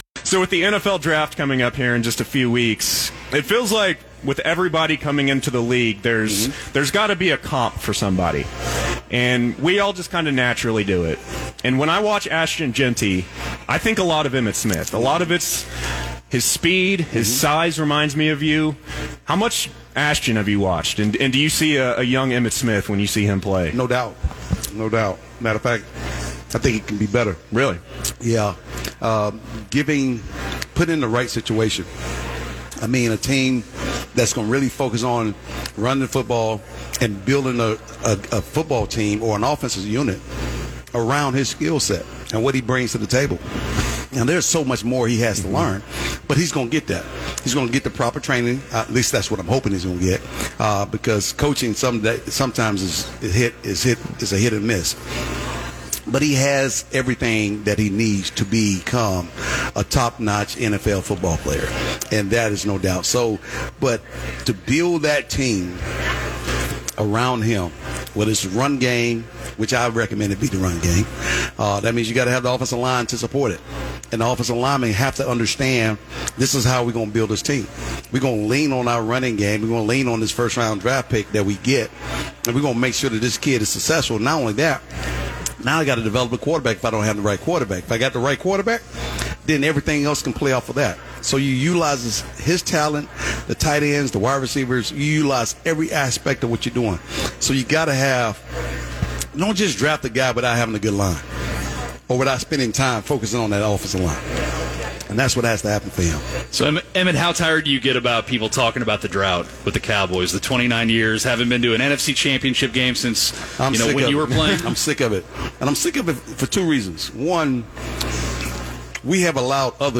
Emmitt Smith was on 96.7 The Ticket where he bitched and moaned about being sick of hearing “Go Birds’ everywhere he goes now that the Eagles just won their second Super Bowl: